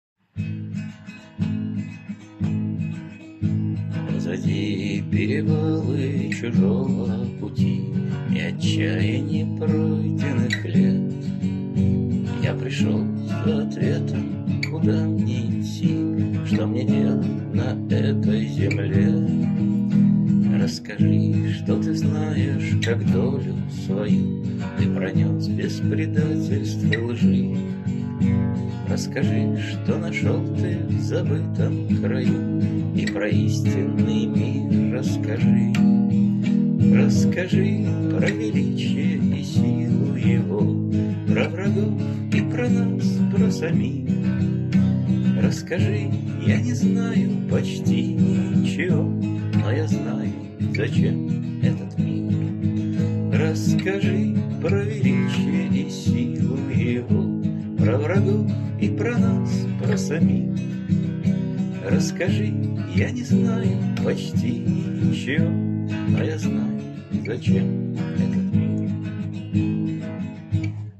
(отрывки из "гитарной оперы")